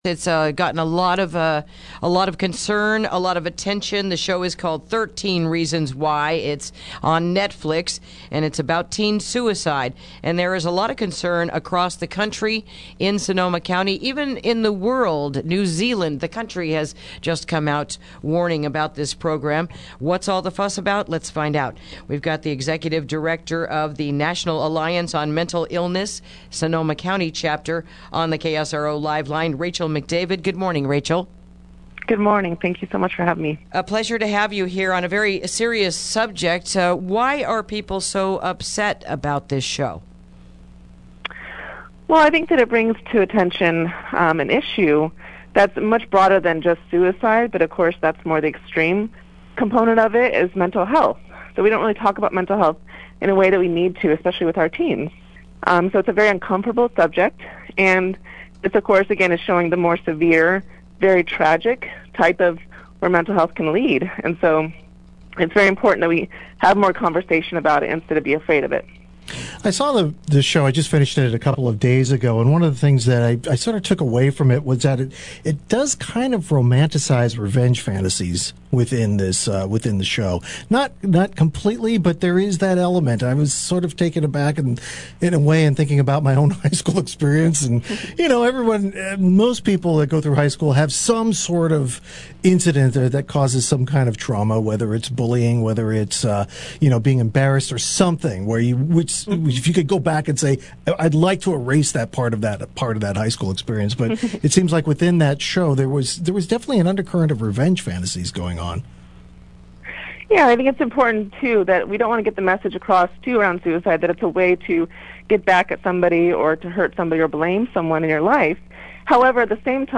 Interview: Teen Suicide Warning with 13 Reasons Why